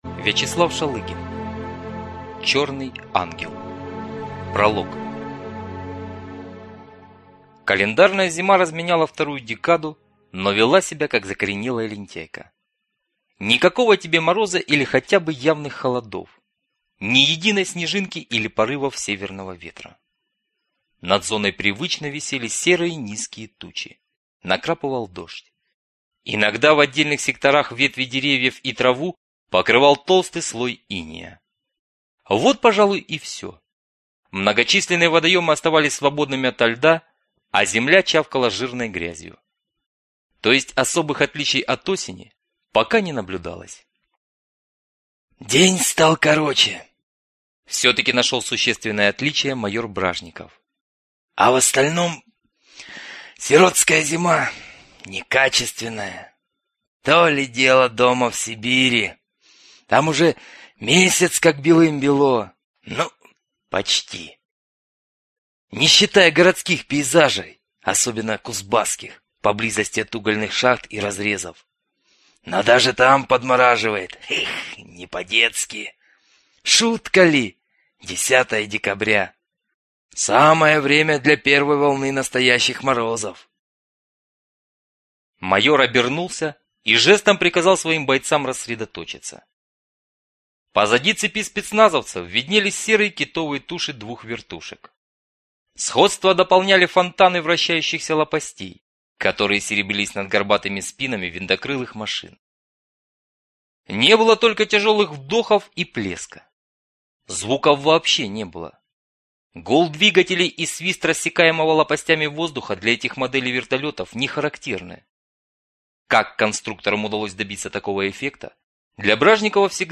Аудиокнига Черный Ангел | Библиотека аудиокниг